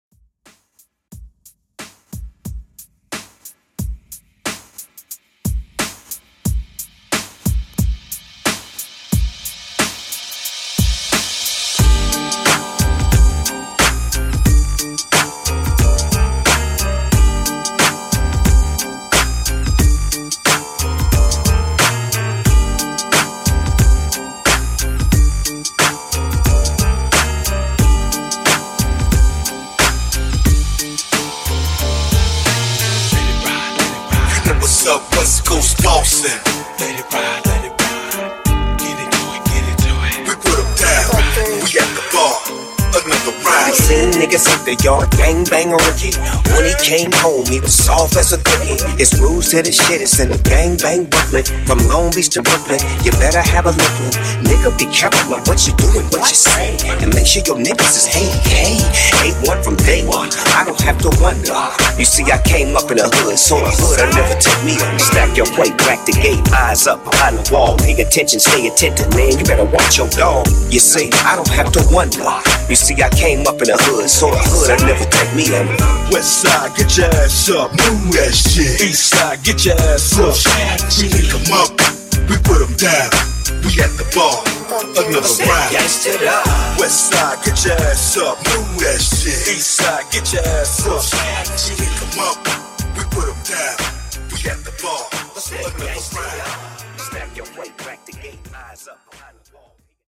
HipHop Redrum)Date Added